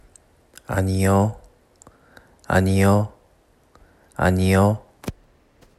■「いいえ」の発音
「アニヨ」と発音する時も一本調子でなるべく抑揚をつけない方が良いです。